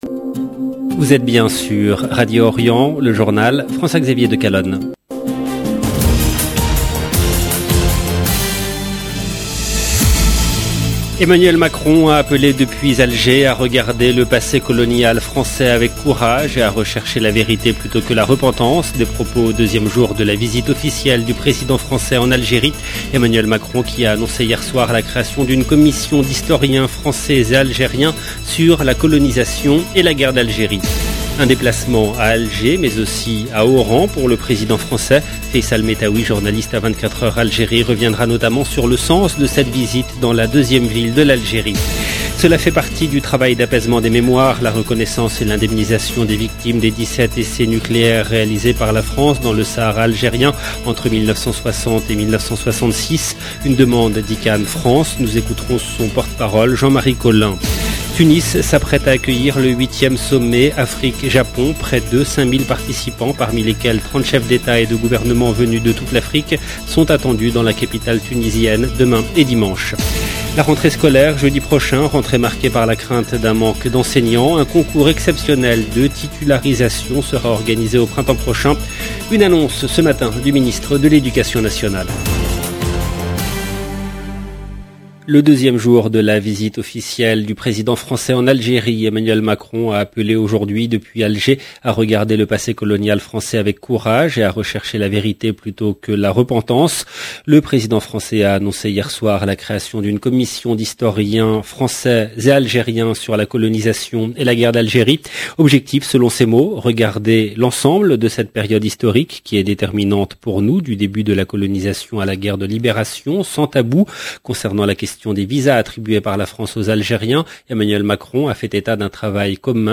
EDITION DU JOURNAL DU SOIR EN LANGUE FRANCAISE DU 26/8/2022